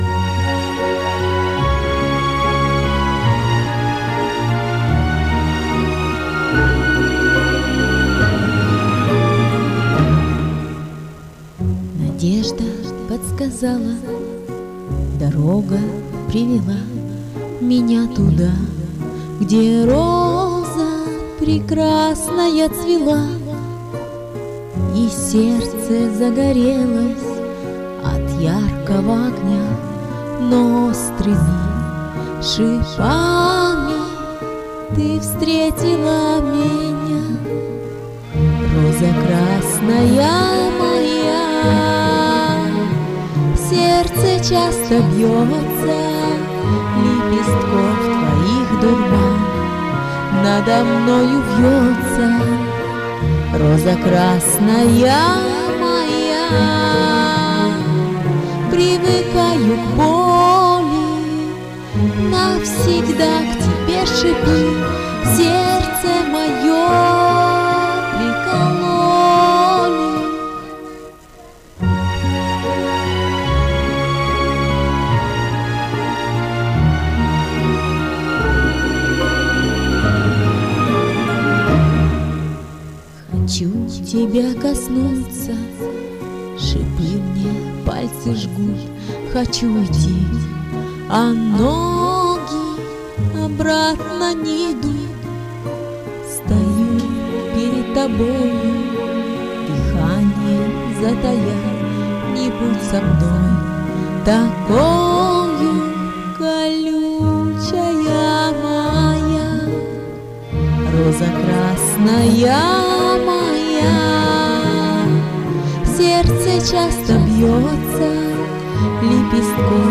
Да со звуком проблемы..........для меня не критично.